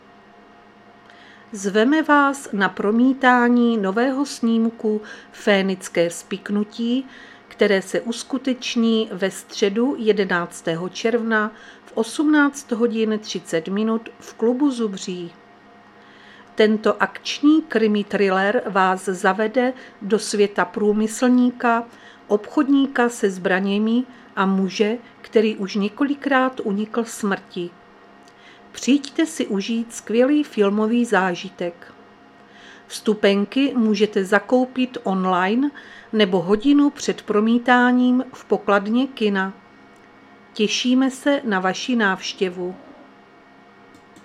Záznam hlášení místního rozhlasu 11.6.2025
Zařazení: Rozhlas